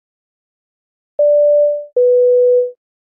На этой странице собраны реалистичные звуки двигателя самолета: от плавного гула турбин до рева при взлете.
Звуковое предупреждение о необходимости пристегнуть ремни в самолете